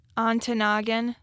way to say it is on-ta-NAW-gan.